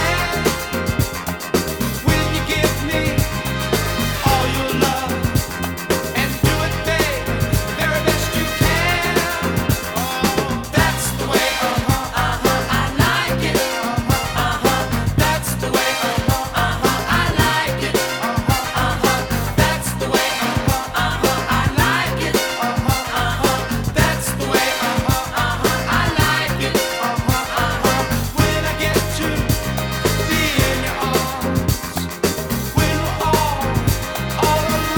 Жанр: Поп музыка / Рок / R&B / Танцевальные / Соул / Диско